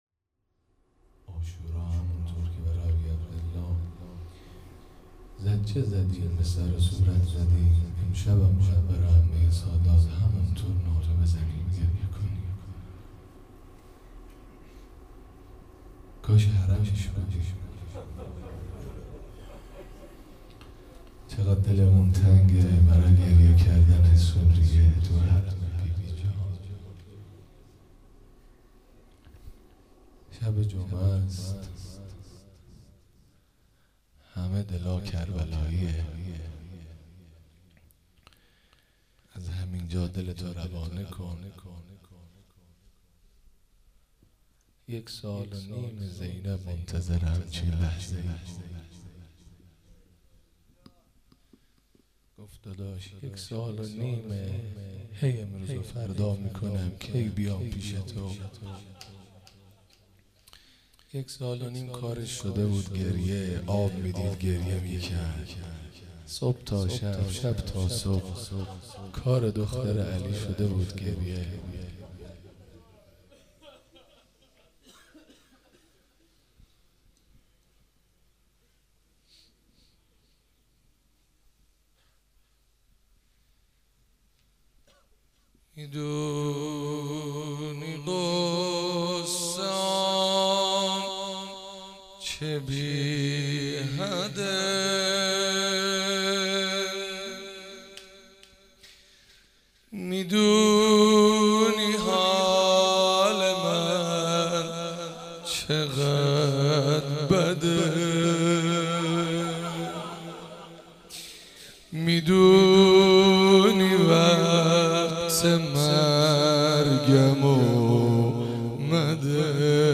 مراسم هفتگی 24 فروردین 96
چهاراه شهید شیرودی حسینیه حضرت زینب (سلام الله علیها)